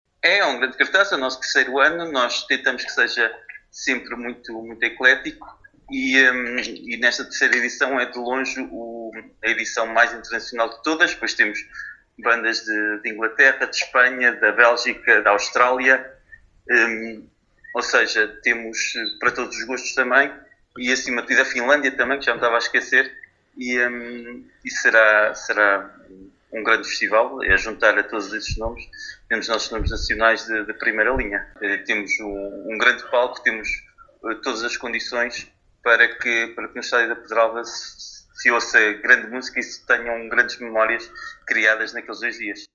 Paulo Marques, Presidente do Município de Vila Nova de Paiva, em declarações à Alive FM, falou do programa do Barrelas Summer Fest 2026, “é a edição mais internacional de todas…”.